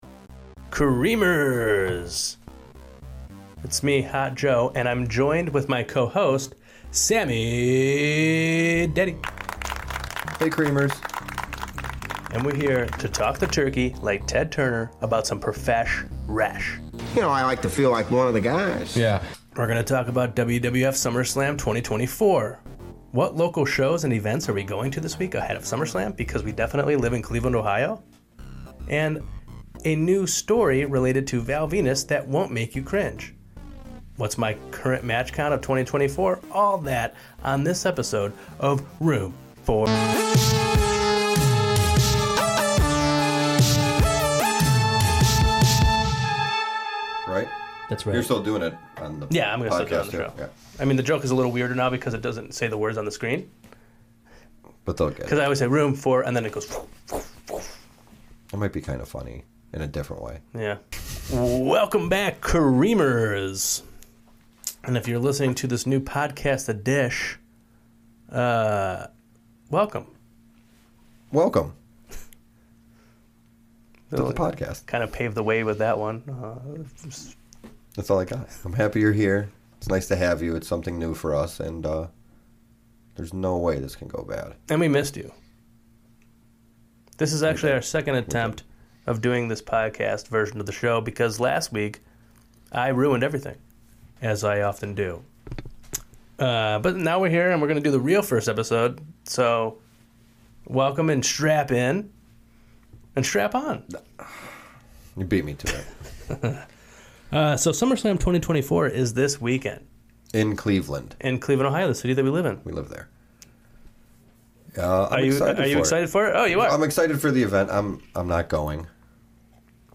Our beloved heroes talk the turkey about WWF Summerslam 2024, the lil thangs happening in the city of Cleveland (where they're definitely from) leading into the pape, and there's even a special phone interview with referee